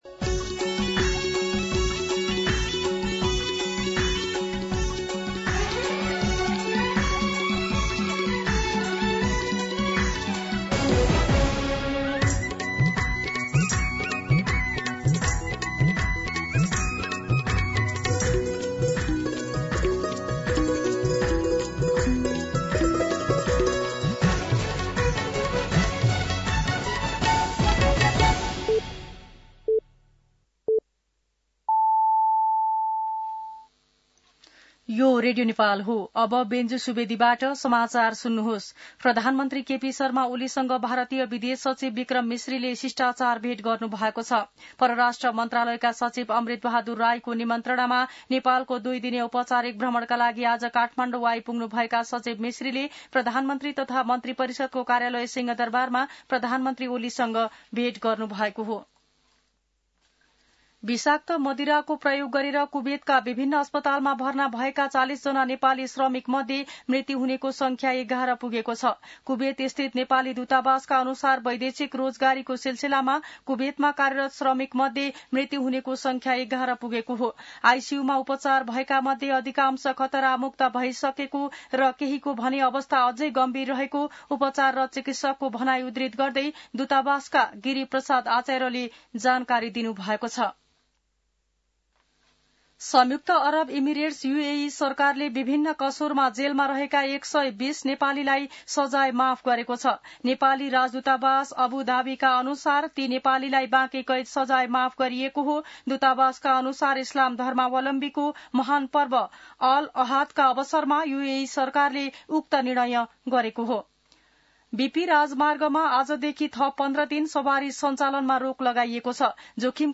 मध्यान्ह १२ बजेको नेपाली समाचार : १ भदौ , २०८२